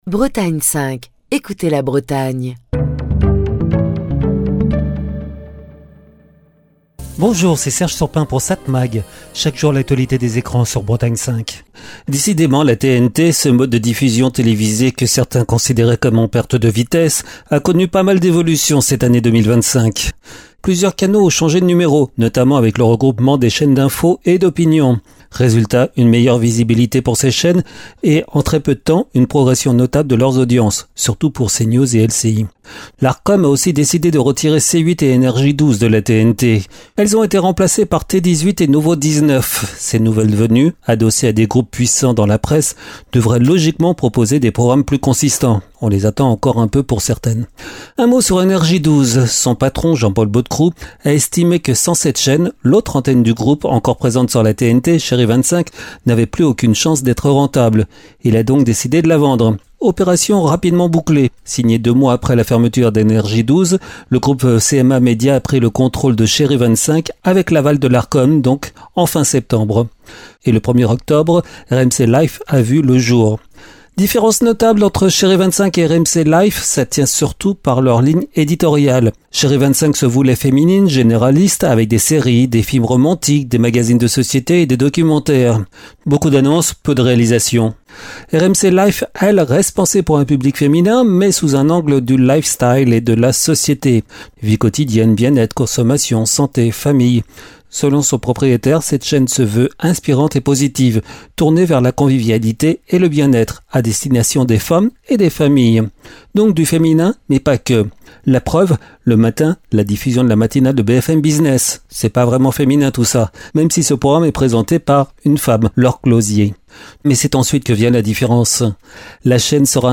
Chronique du 2 octobre 2025.